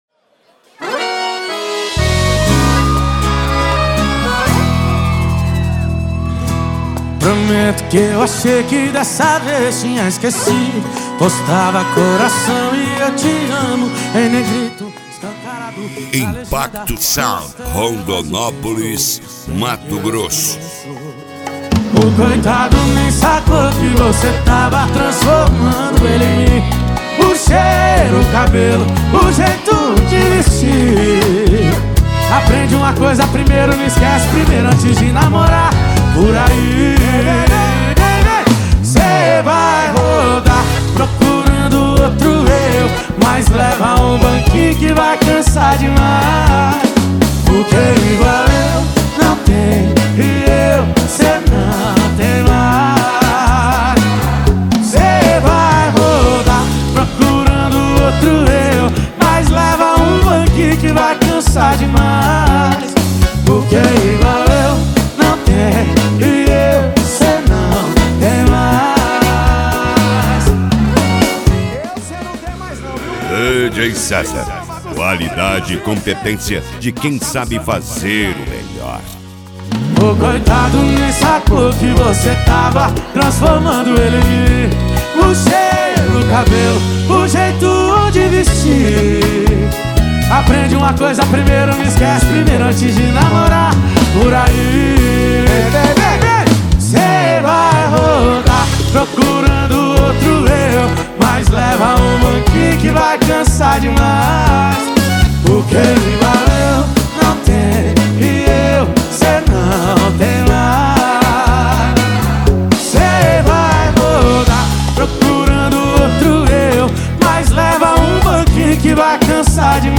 Modao
SERTANEJO
Sertanejo Raiz
Sertanejo Universitario